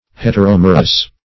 Heteromerous \Het`er*om"er*ous\, a. [See Heteromera.]
heteromerous.mp3